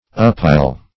Uppile \Up*pile"\